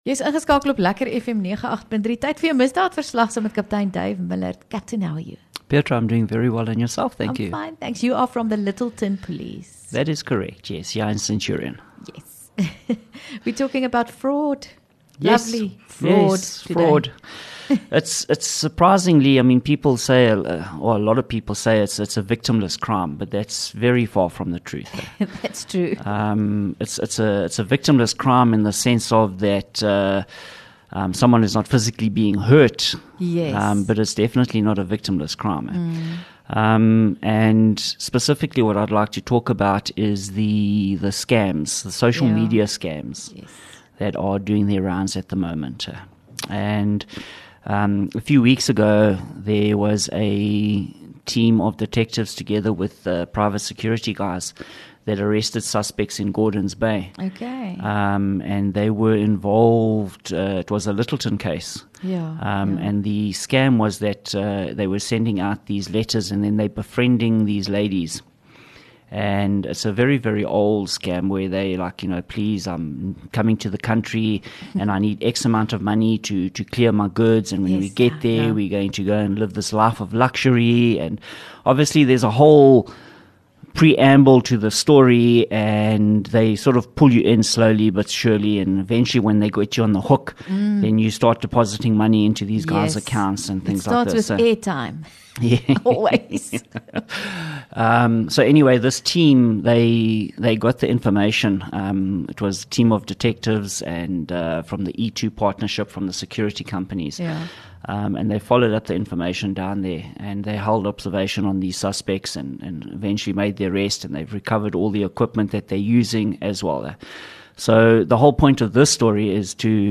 LEKKER FM | Onderhoude 5 Nov Misdaad Verslag